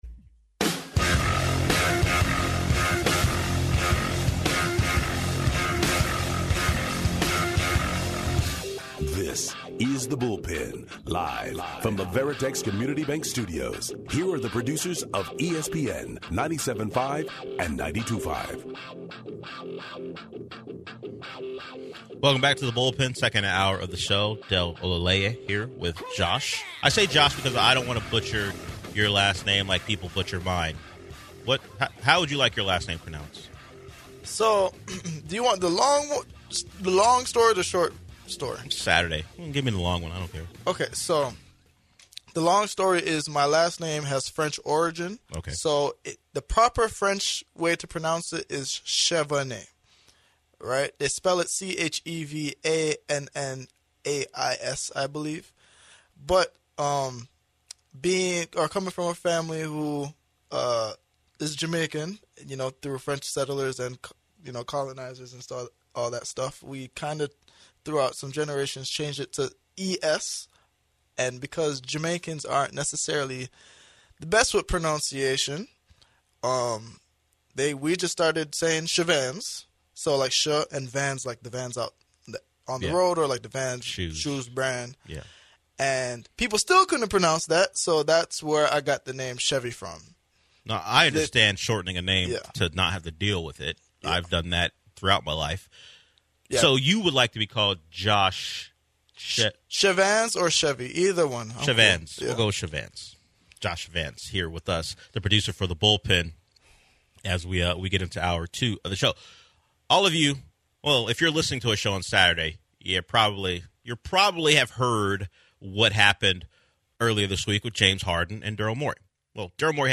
running as a solo host